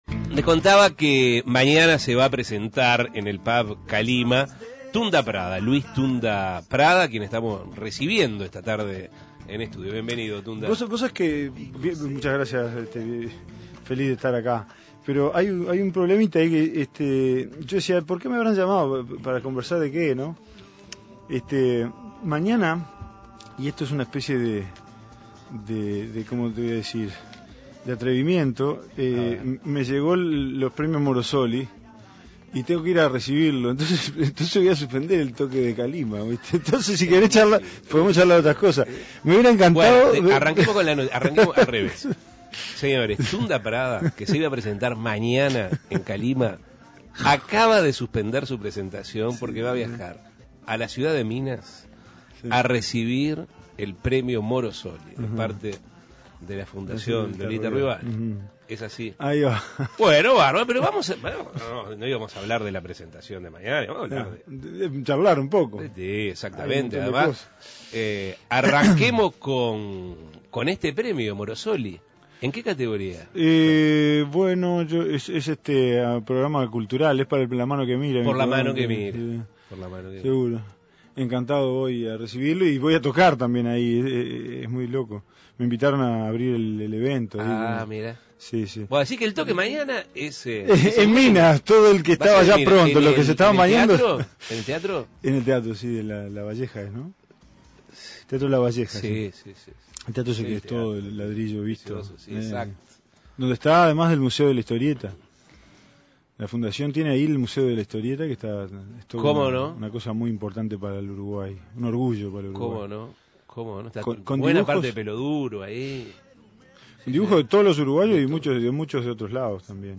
Entrevistas La mano que dibuja Imprimir A- A A+ Antes de recibir un premio Morosoli por La Mano que Mira